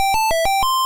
Item_Drop.ogg